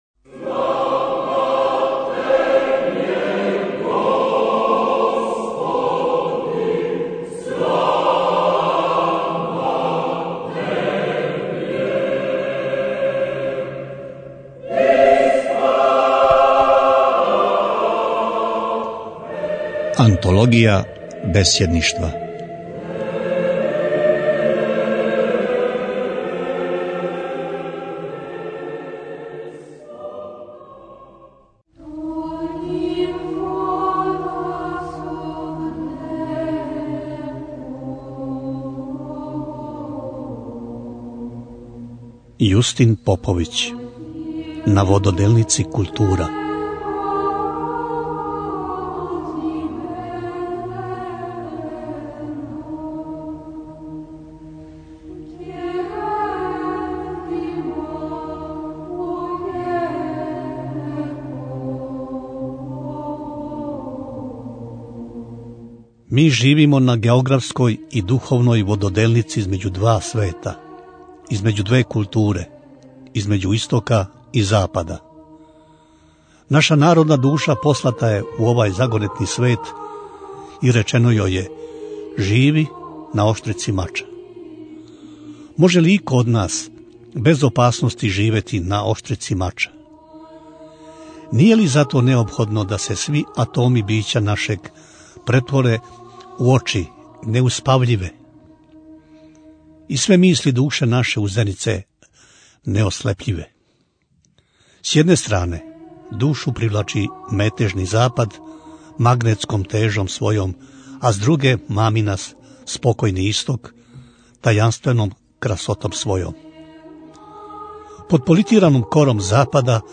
Преузмите аудио датотеку 4707 преузимања 1407 слушања Отац Јустин Поповић - На вододелници култура Tagged: Антологија бесједништва 7:16 минута (2.08 МБ) Бесједа оца Јустина Поповића: "На вододелници култура".